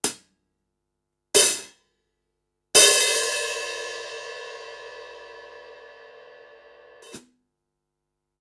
Échantillons sonores Audio Technica AT-4050
Audio Technica AT-4050 - Hi Hats